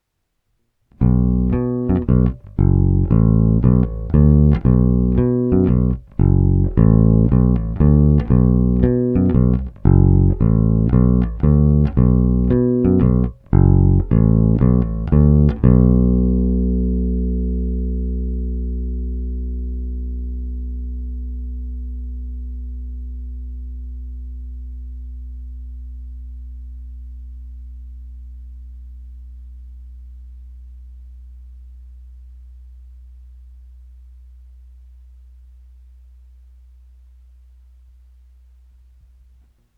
Ne tak zvonivý, ale zato parádně pevný, tučný, a to i s přihlédnutím k tomu, že jsem basu dostal s hlazenkami s nízkým tahem.
Povinné jsou s plně otevřenou tónovou clonou rovnou do zvukovky a jen normalizovány, bonusové jsou se simulacemi aparátu.
Hra u kobylky